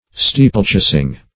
Search Result for " steeplechasing" : The Collaborative International Dictionary of English v.0.48: Steeplechasing \Stee"ple*chas`ing\ (-ch[=a]s`[i^]ng), n. The act of riding steeple chases.